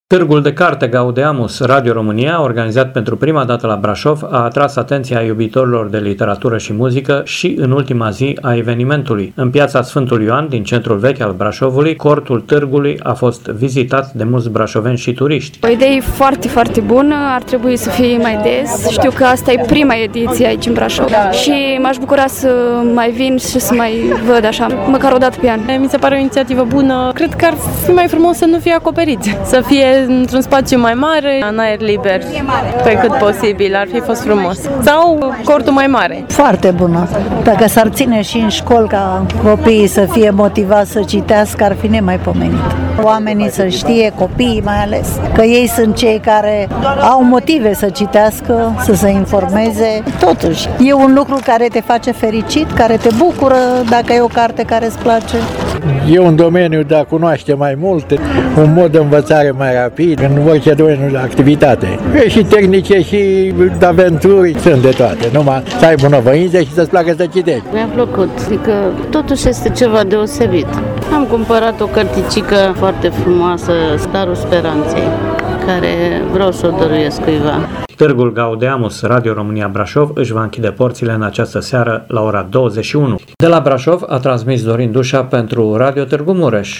În Piața Sf. Ioan, din centrul vechi al Brașovului, cortul Târgului a fost vizitat de mulți brașoveni și turiști: